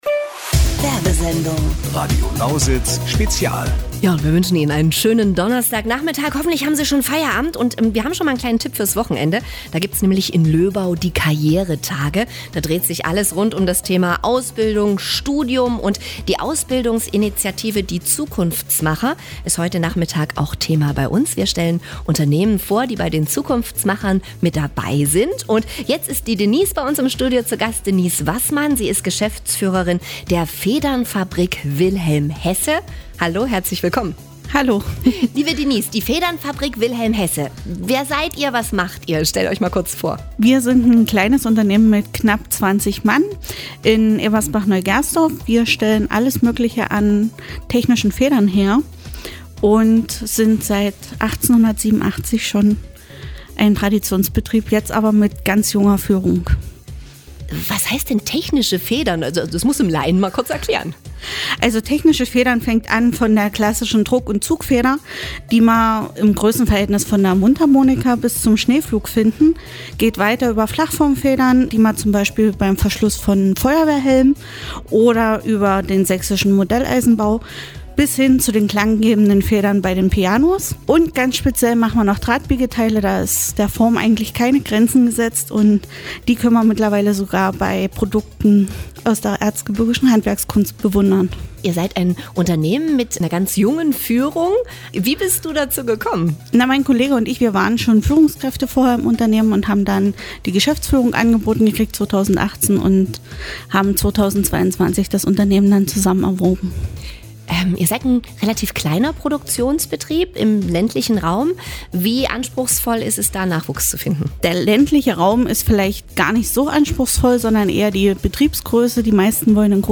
Das Ausbildungscluster Zukunftsmacher war am 26. September zu Gast in einer Sondersendung bei Radio Lausitz.